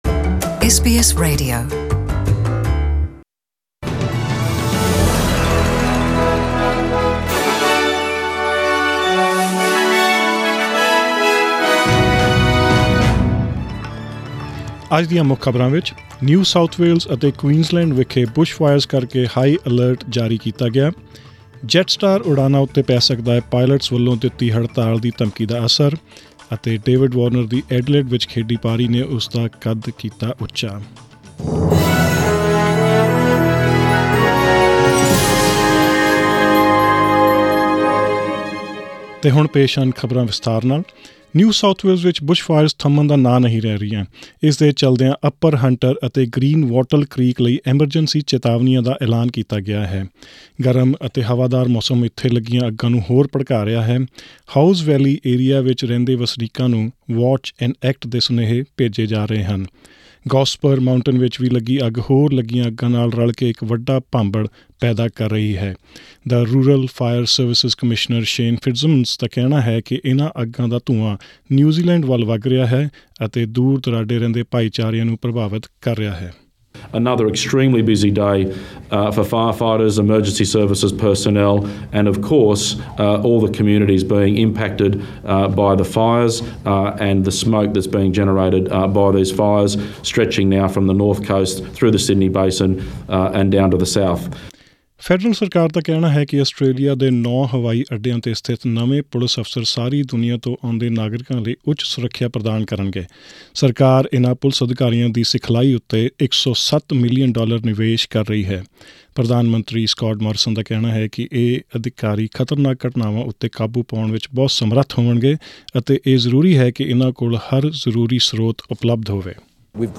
SBS Punjabi News: 6 December 2019